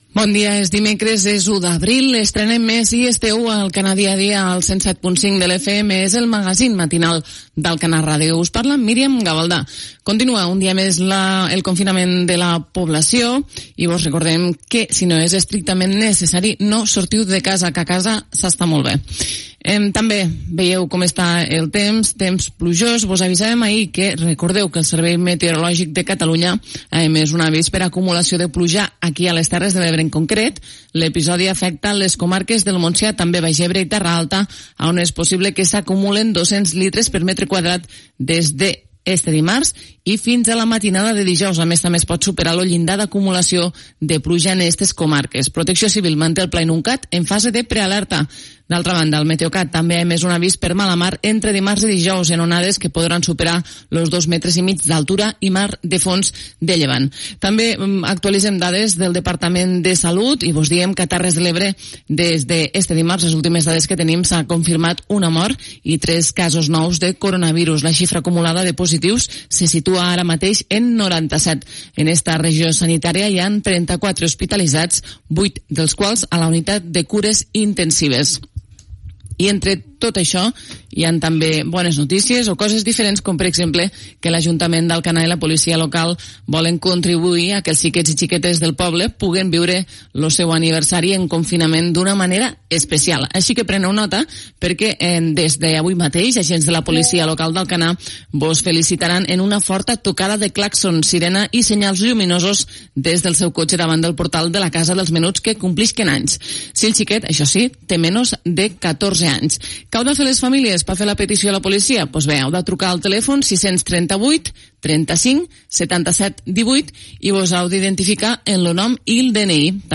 Senyals horaris, sintonia de La Xarxa i careta del programa.
Info-entreteniment